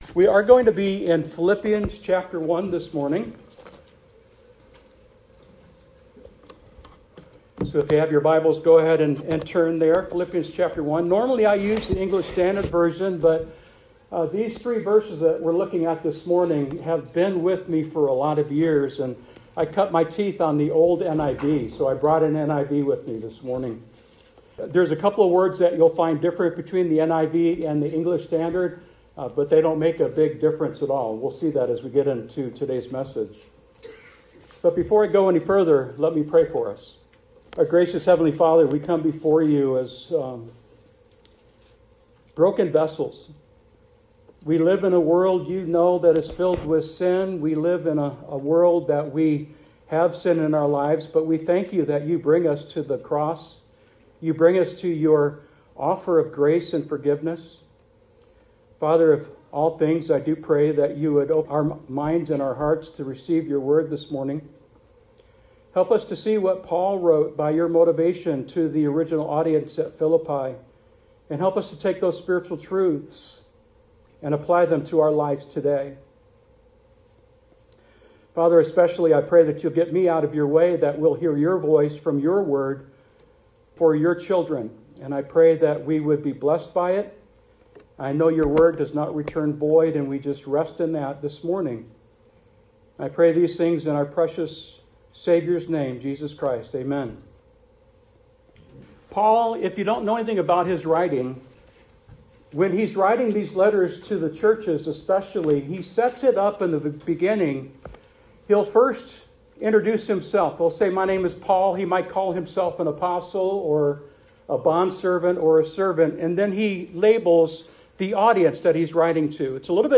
NOTE: As our Hallelujah Hands Puppet Ministry Team performed their Christmas program at FBC, I was blessed with the opportunity to fill the pulpit at Community Bible Chapel (CBC) here in Norway. This is the audio recording of that sermon.